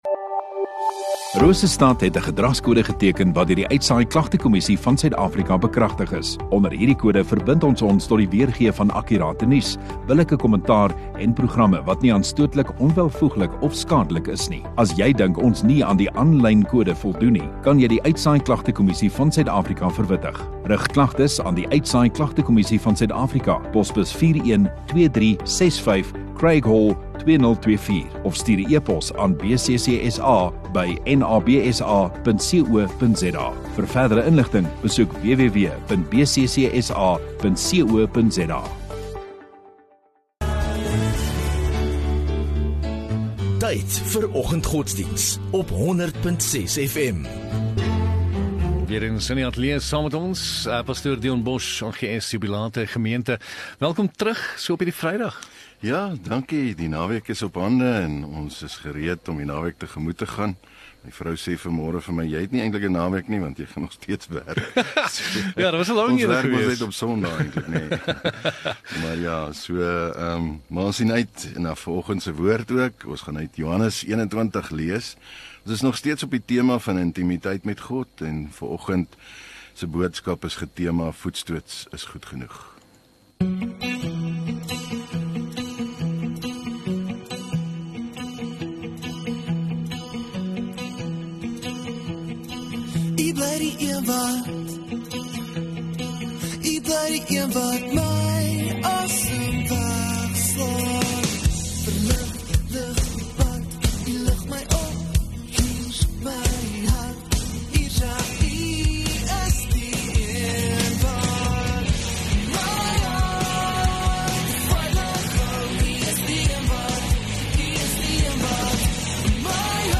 28 Jun Vrydag Oggenddiens